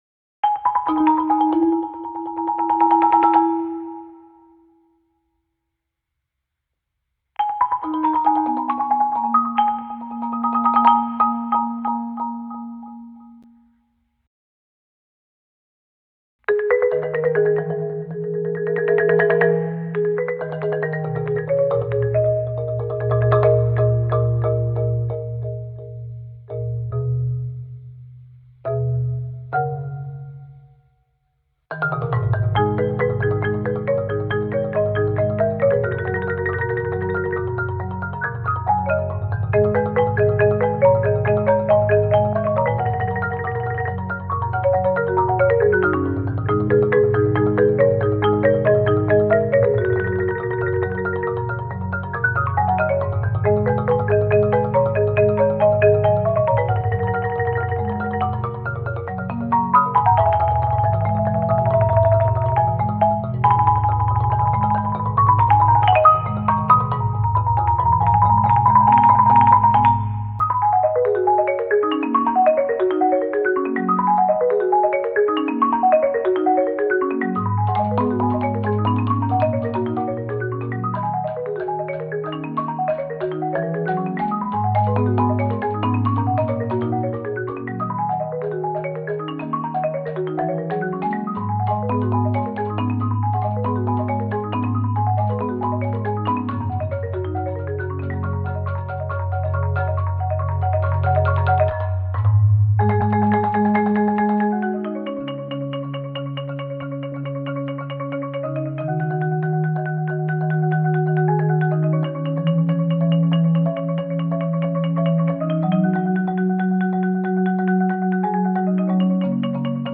Voicing: 3 players